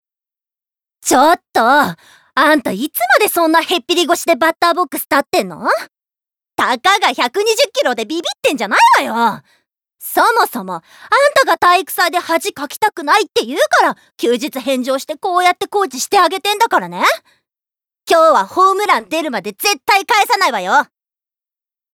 Voice Sample
ボイスサンプル
セリフ３